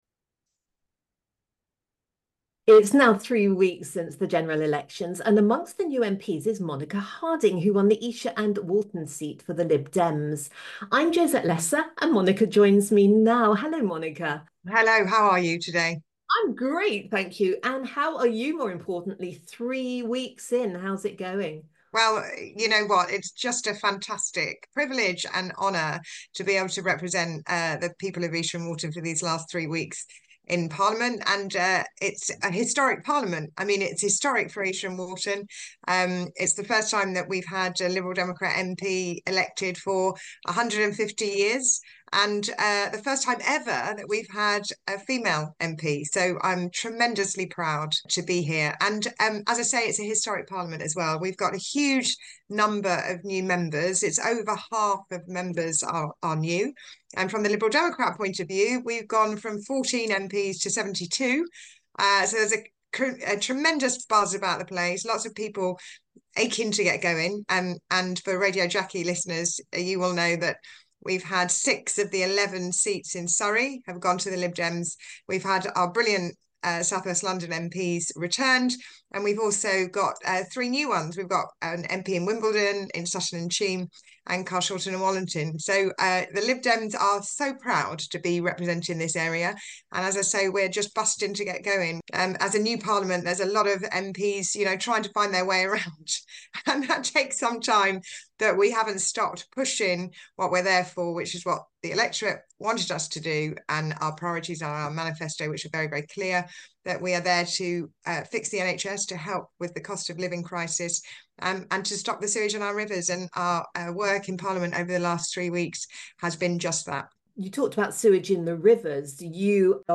Monica Harding MP, speaks with Jackie reporter